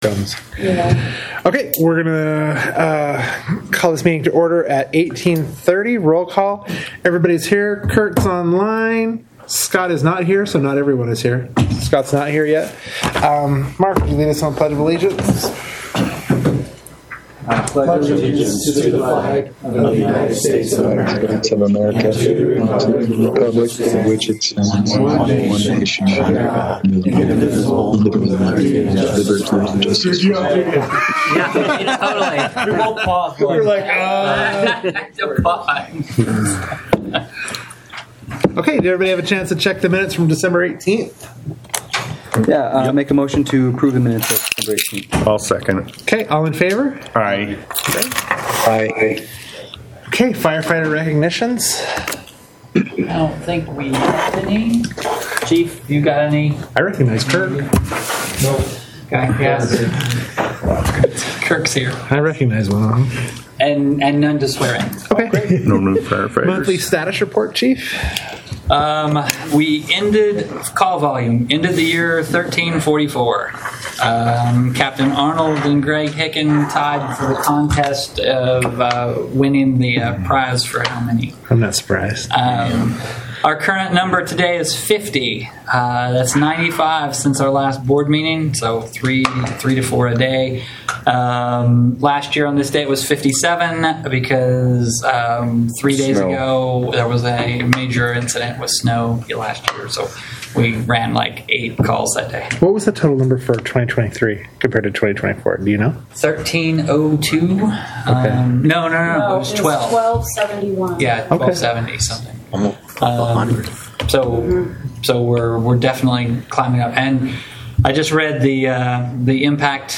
Board Meeting
Notice is hereby given that the North Tooele Fire Protection Service District will hold a board meeting on January 15, 2025 at 6:30 p.m. at the Stansbury Park Fire Station, 179 Country Club, Stansbury Park, UT.